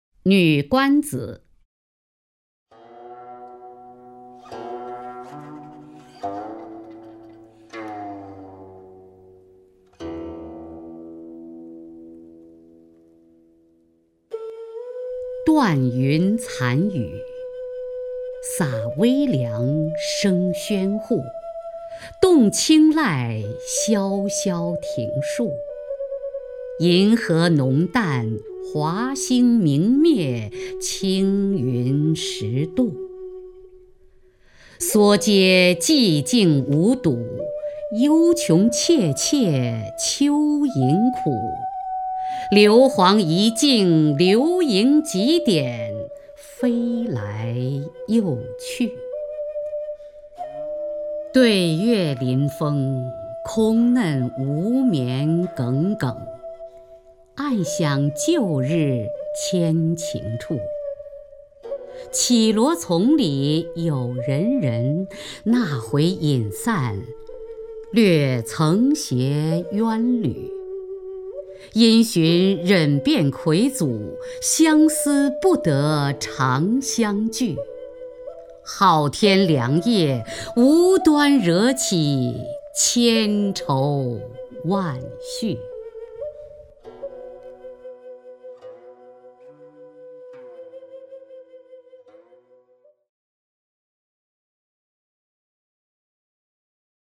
首页 视听 名家朗诵欣赏 雅坤
雅坤朗诵：《女冠子·断云残雨》(（北宋）柳永)